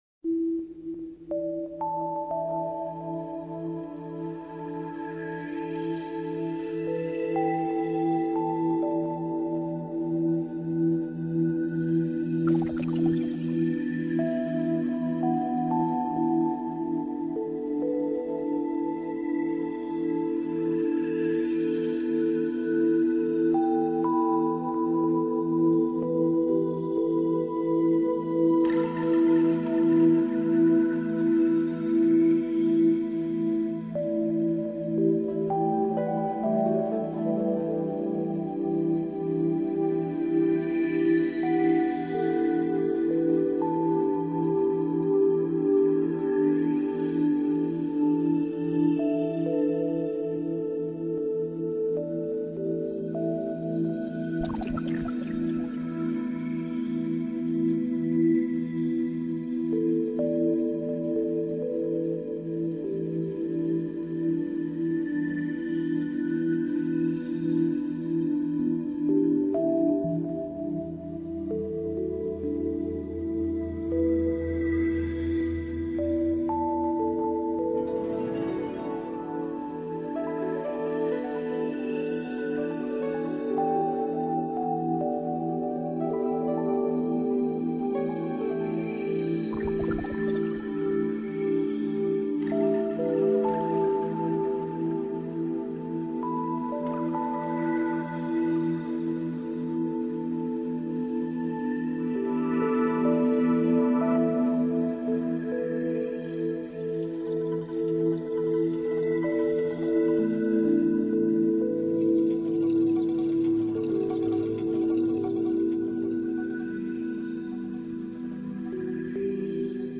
Musique relaxation anti -stress
2025 MUSIQUE RELAXANTE, EFFETS SONORES DE LA NATURE audio closed https
Musique-relaxation-anti-stress.mp3